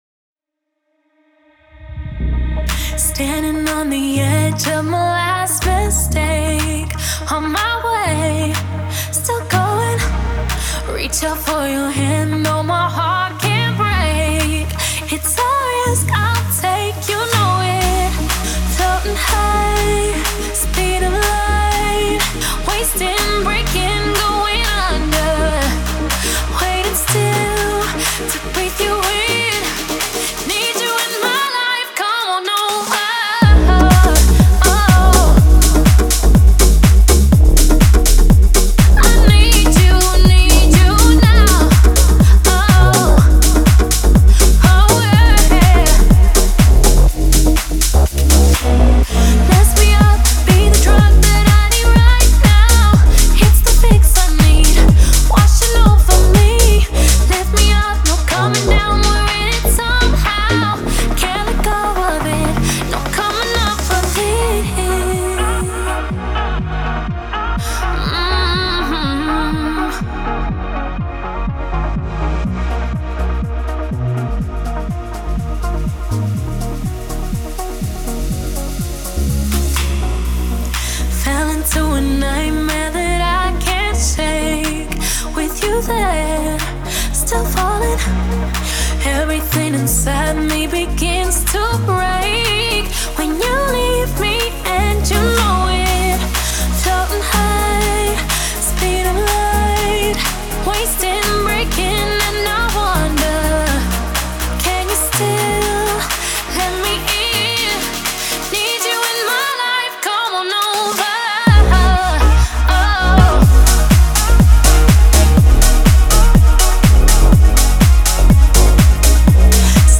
энергичная поп-песня